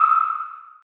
sonar0.mp3